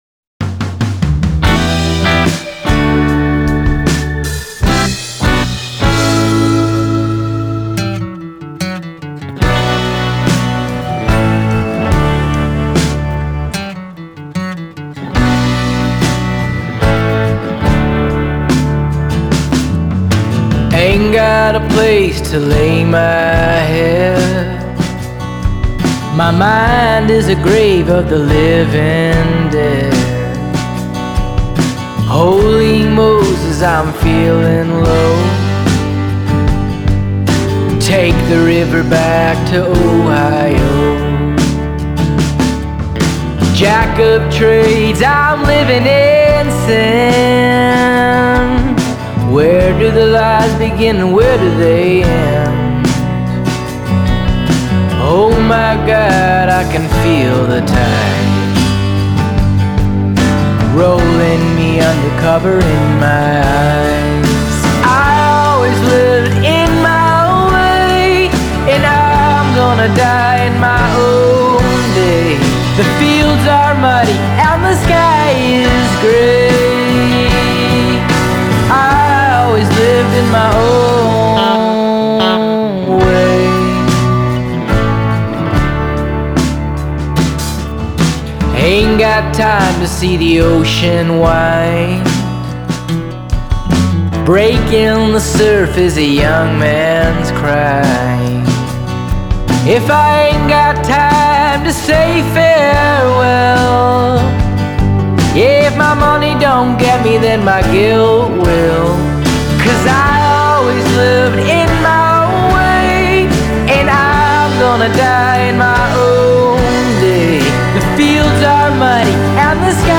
Genre: Alt Folk, Americana